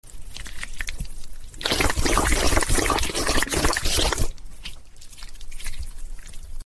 Tongue ASMR MP3 Download
The Tongue ASMR sound button is from our meme soundboard library
Tongue-ASMR.mp3